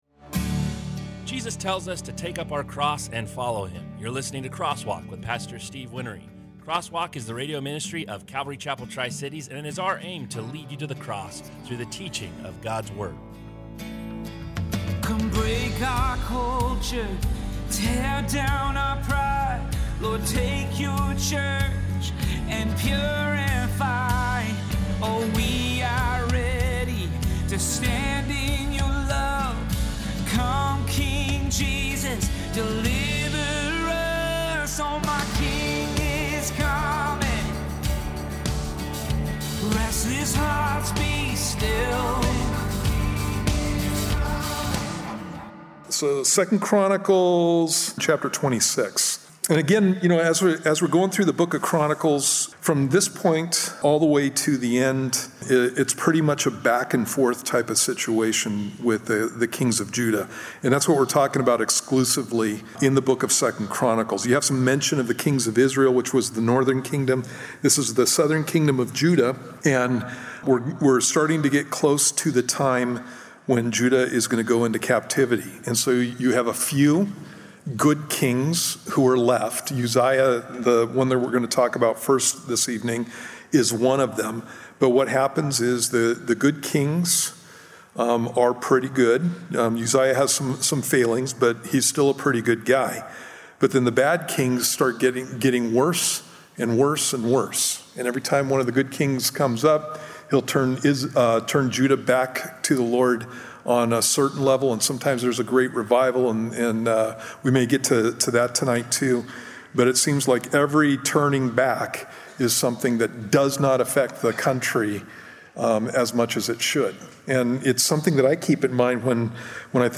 Crosswalk is a verse by verse bible study.